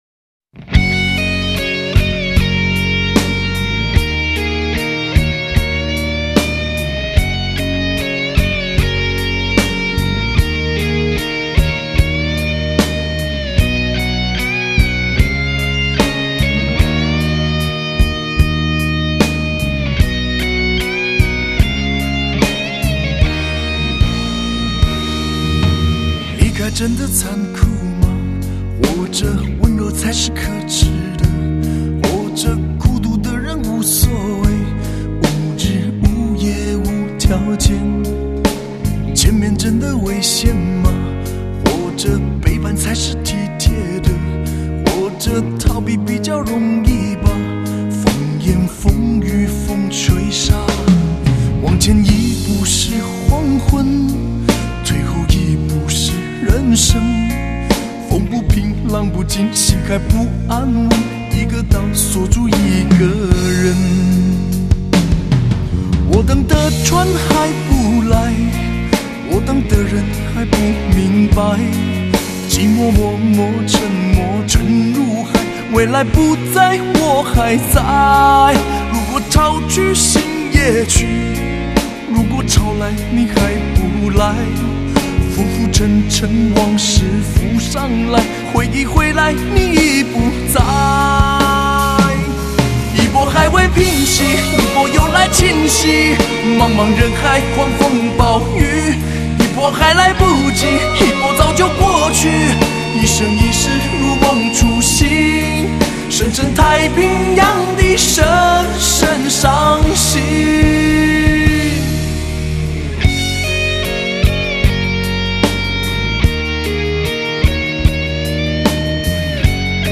這就是 雙軌的聲音